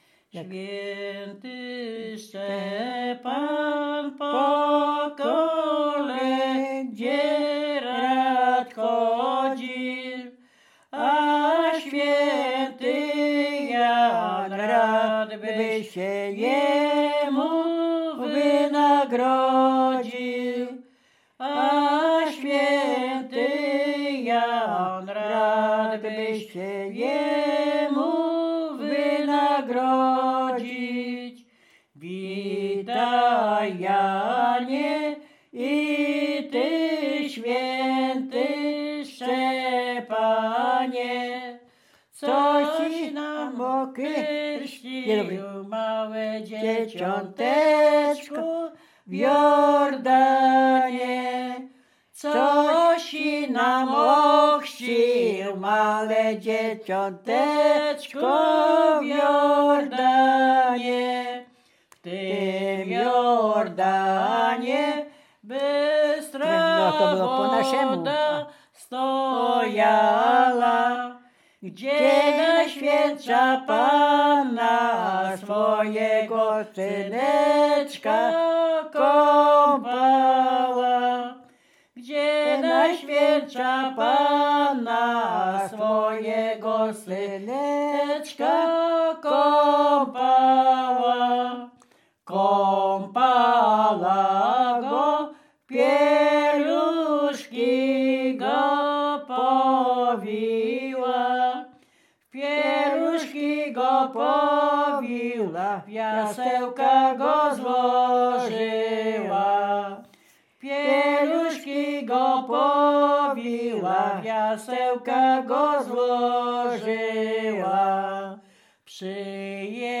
Dolny Śląsk
Kolęda